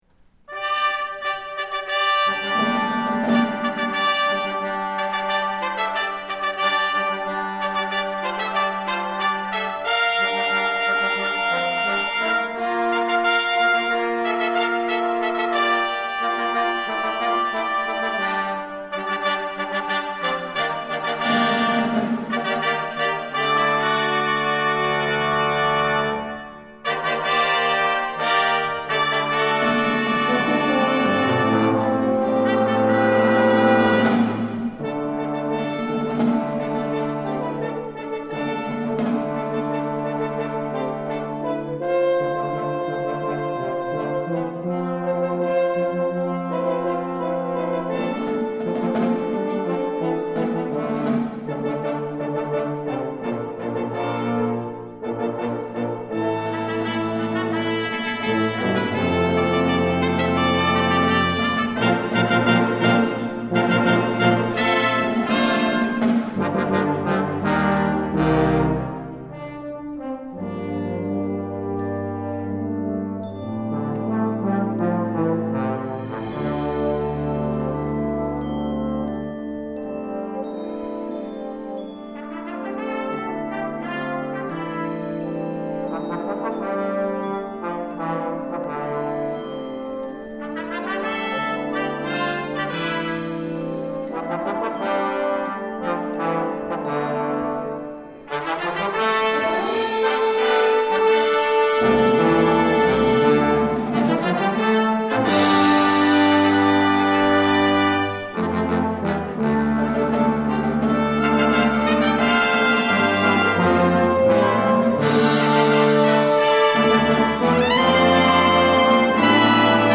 Voicing: Brass Ensemble